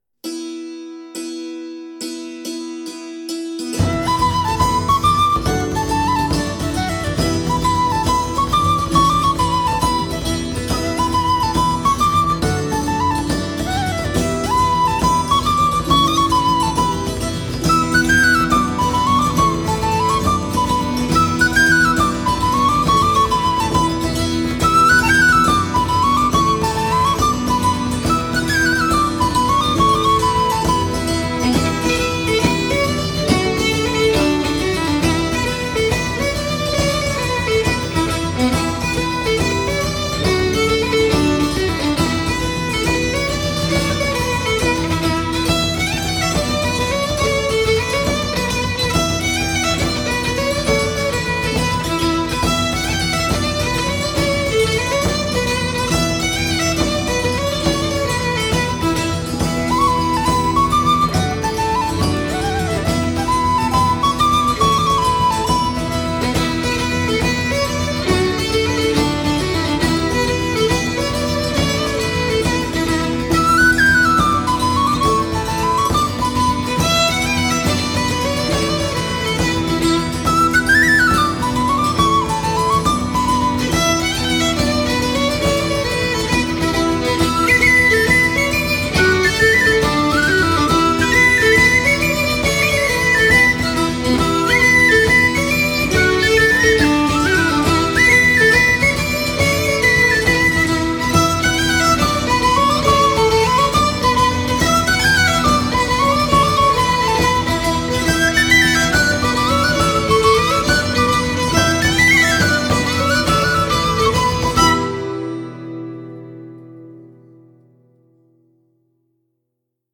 Кельтская